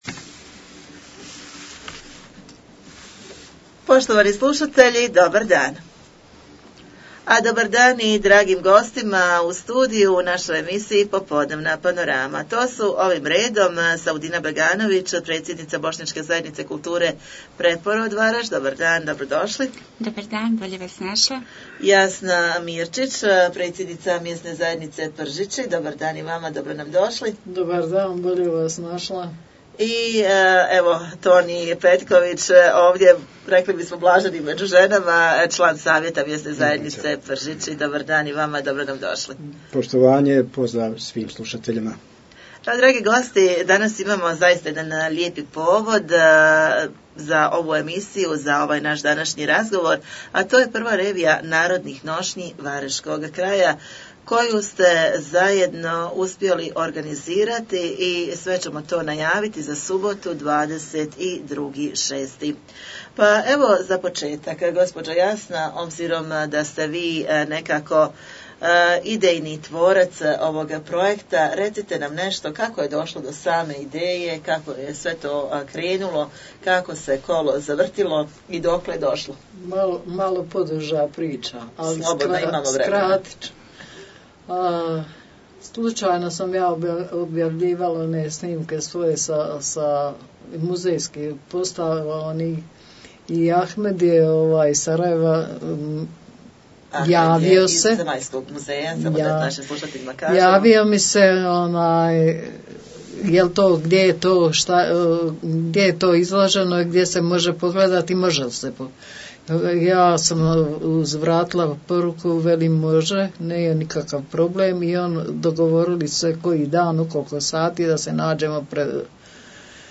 U studiju smo ugostili